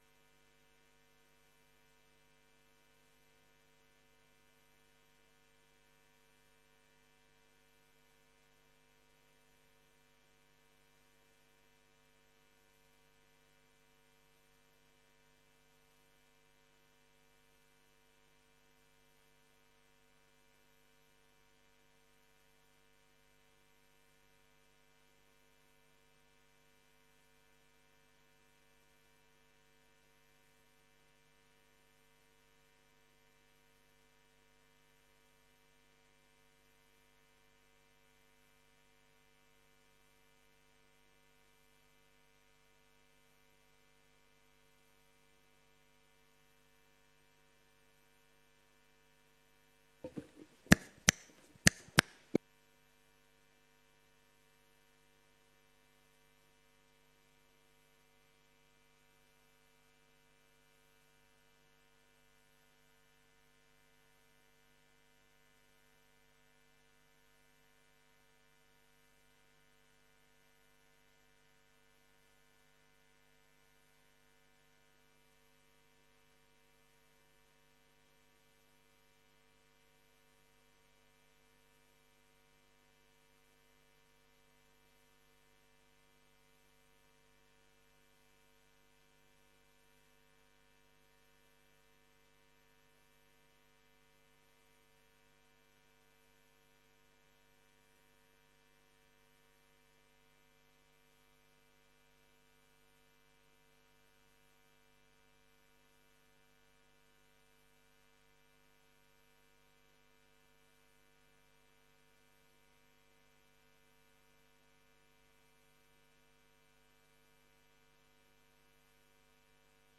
Locatie: De Lockhorst, Sliedrecht